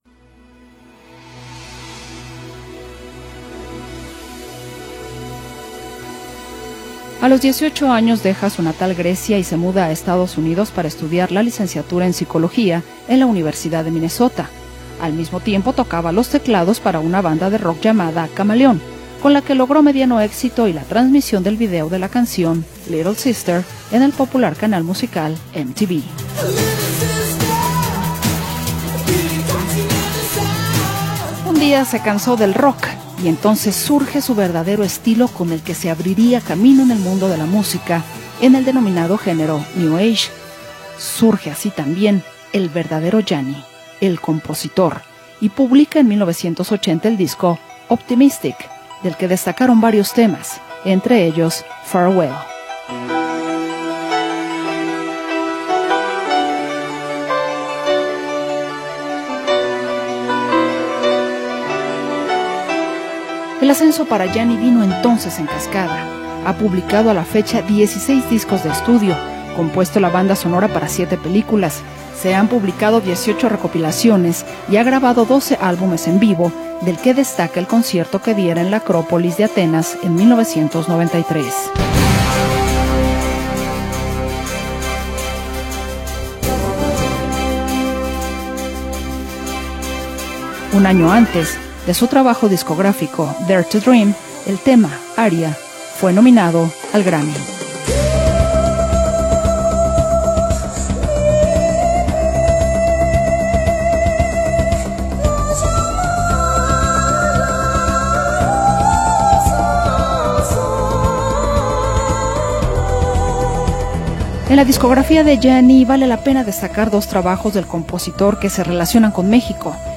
El pianista y compositor griego